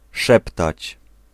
Ääntäminen
Synonyymit susurrer Ääntäminen France: IPA: [ʃy.ʃɔ.te] Haettu sana löytyi näillä lähdekielillä: ranska Käännös Ääninäyte 1. szeptać Määritelmät Verbit Parler bas et mystérieusement , en remuant à peine les lèvres .